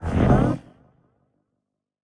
Minecraft Version Minecraft Version 1.21.4 Latest Release | Latest Snapshot 1.21.4 / assets / minecraft / sounds / mob / warden / listening_2.ogg Compare With Compare With Latest Release | Latest Snapshot
listening_2.ogg